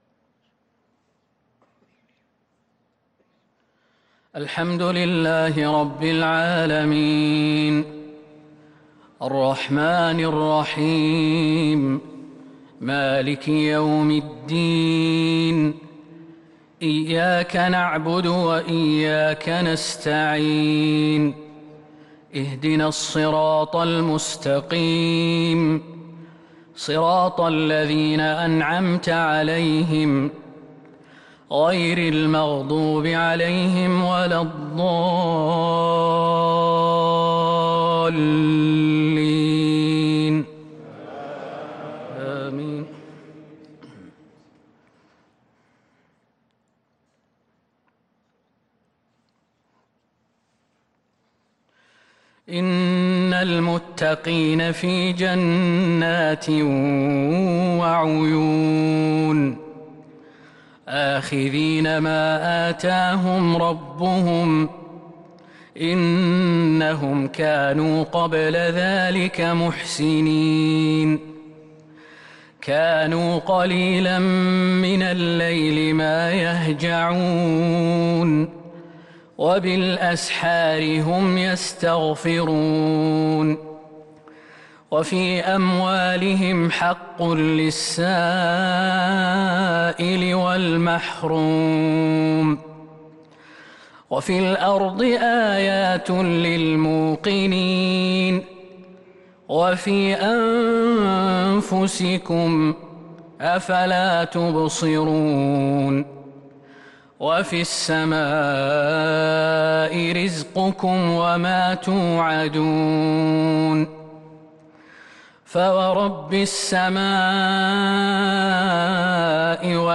صلاة المغرب للقارئ خالد المهنا 6 جمادي الأول 1443 هـ